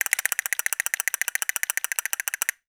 02 CASTANETS.wav